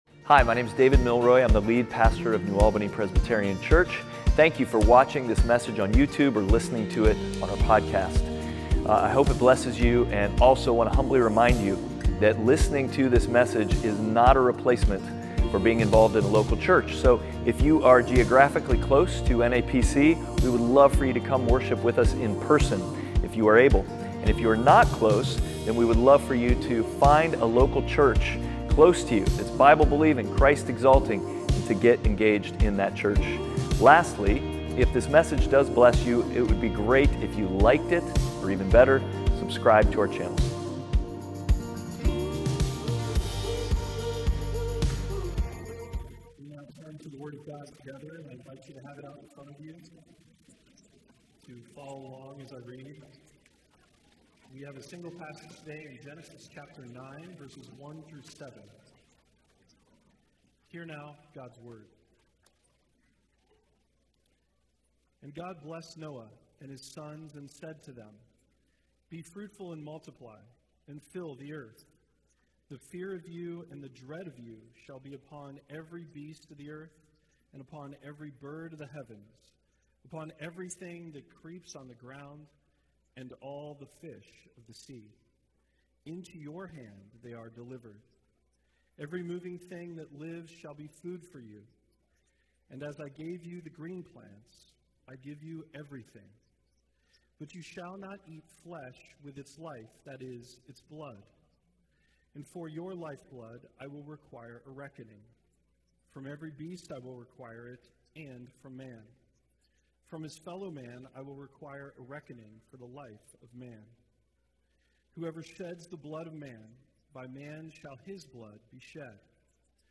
Passage: Genesis 9:1-7 Service Type: Sunday Worship